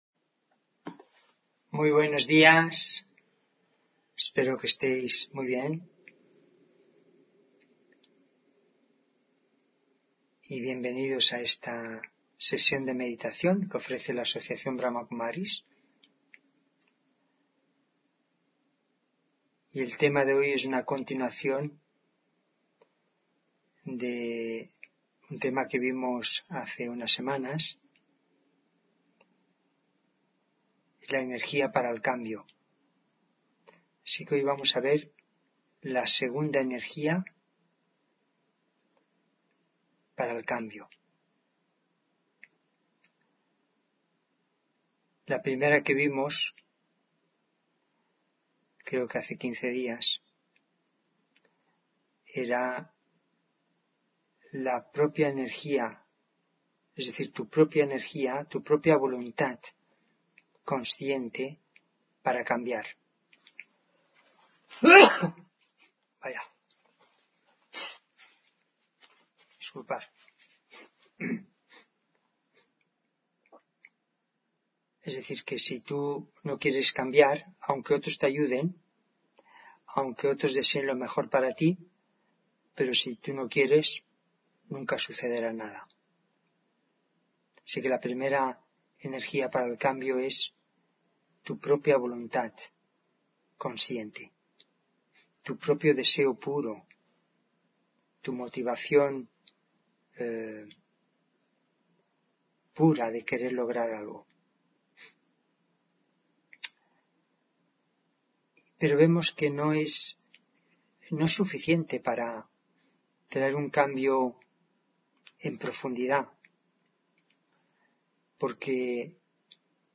Meditación de la mañana: 2ª Energía para el cambio